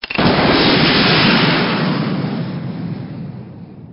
rpg7_fire.ogg